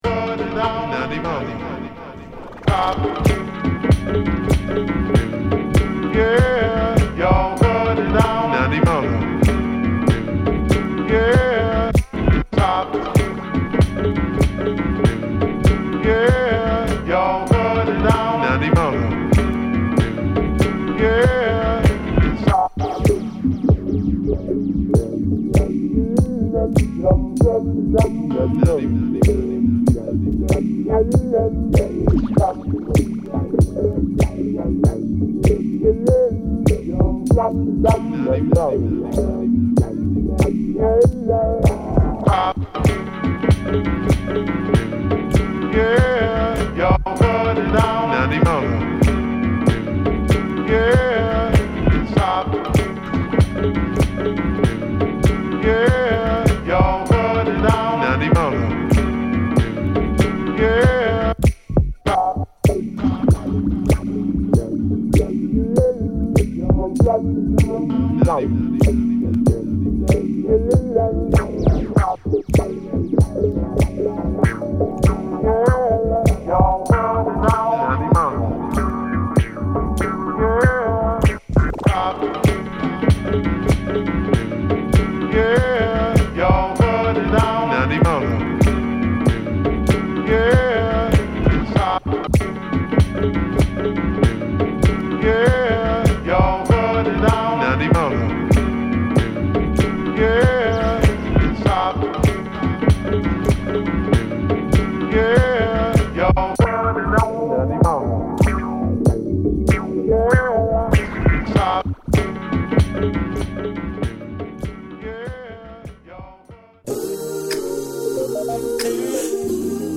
艶かしいソウル・ヴォーカルを小気味良く展開させ